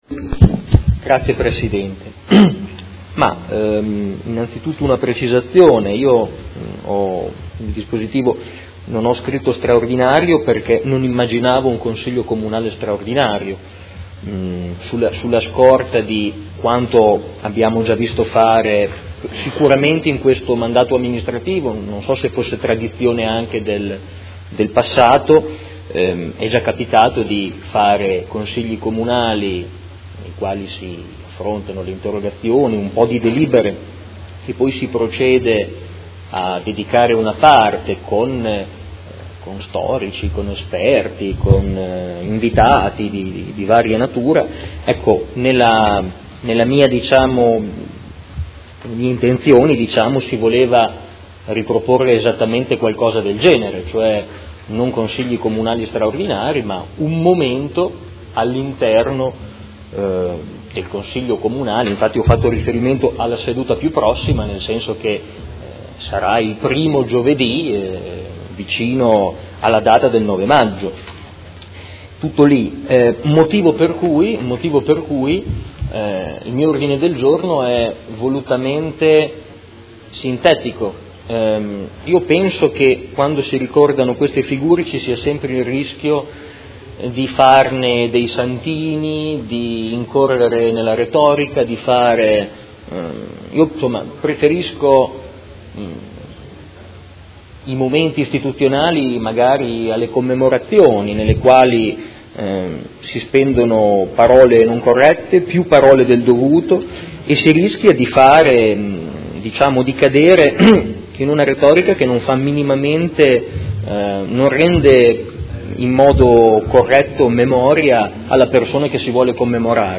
Seduta del 15/03/2018. Dibattito su Mozione presentata dai Consiglieri Forghieri, Pacchioni, Carpentieri, Bortolamasi, De Lillo, Liotti, Poggi, Morini, Venturelli e Fasano (PD) avente per oggetto: Commemorazione per il quarantesimo anniversario dell’uccisione di Aldo Moro e Mozione presentata dal Gruppo Consiliare Art.1-MDP/Per Me Modena avente per oggetto: A quarant'anni dall'assassinio di Aldo Moro